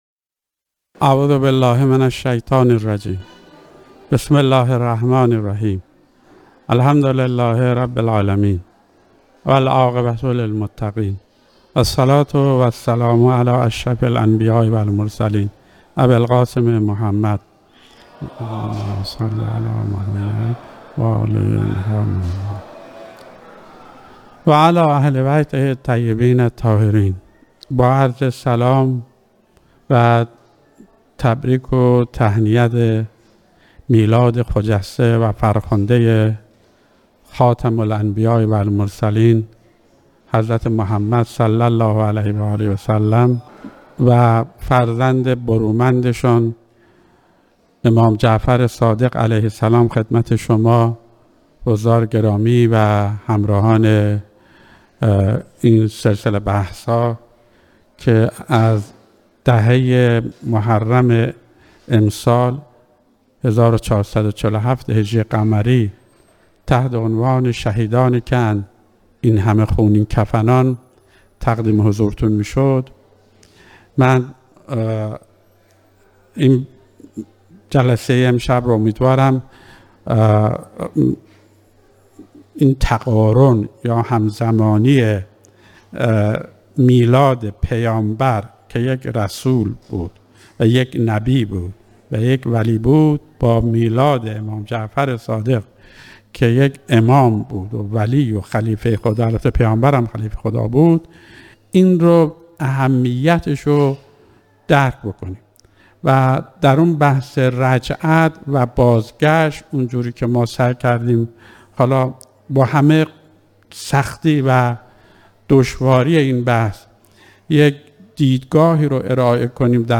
(Farsi; 6 Lectures) Series of lectures in Muharram of 1444 A.H (1401; 2022)